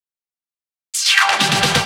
Fill 128 BPM (5).wav